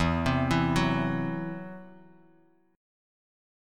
Esus2#5 chord